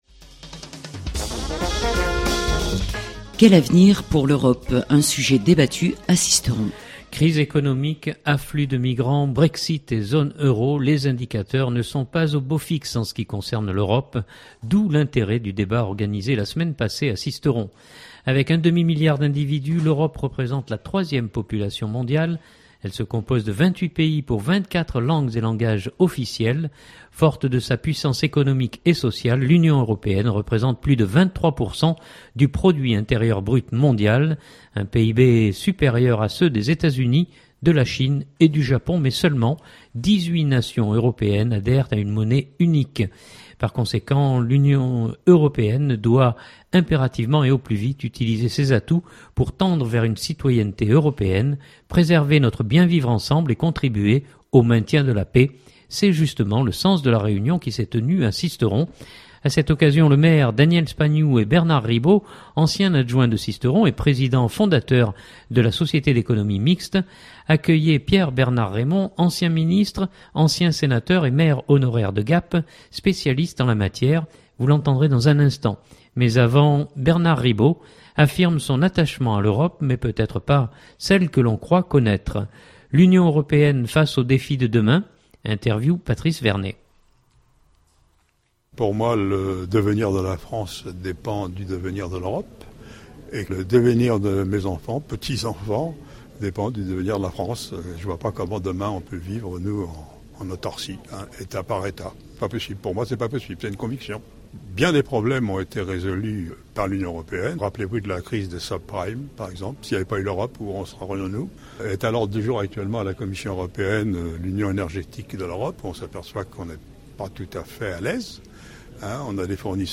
D’où l’intérêt du débat organisé la semaine passée à Sisteron.
A cette occasion le Maire, Daniel Spagnou et Bernard Ribault, ancien adjoint de Sisteron et Président fondateur de la Société d’Economie Mixte, accueillaient Pierre Bernard-Reymond, ancien ministre, ancien sénateur et maire honoraire de Gap, spécialiste en la matière : vous l’entendrez dans un instant. Mais avant, Bernard Ribault affirme son attachement à l’Europe, mais peut-être pas celle que l’on croit connaître. L’Union Européenne face aux défis de demain.